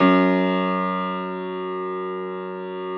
53h-pno04-F0.aif